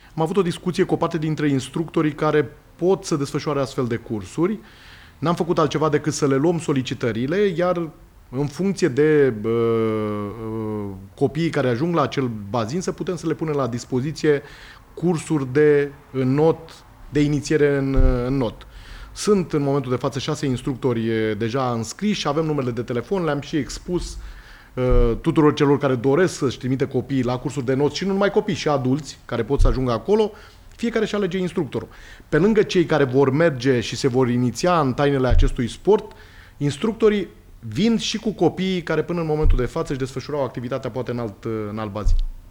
Marcel Romanescu, Primar Târgu Jiu